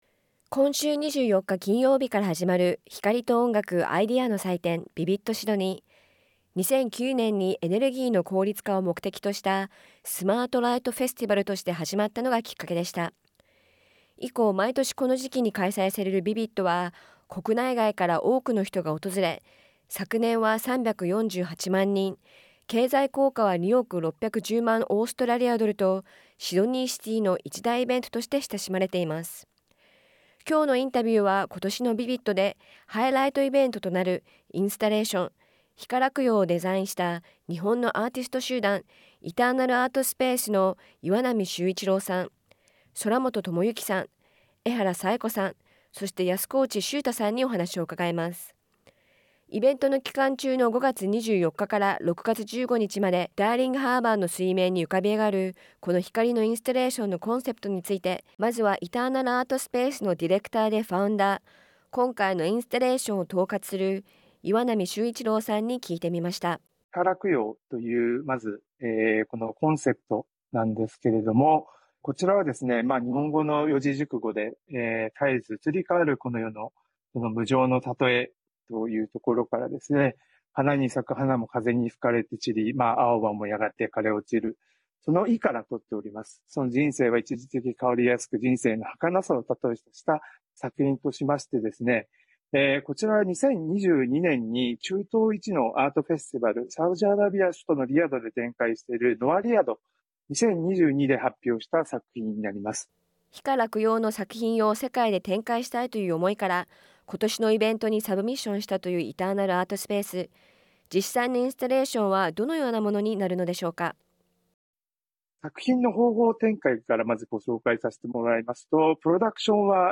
フルインタビューは音声からどうぞ。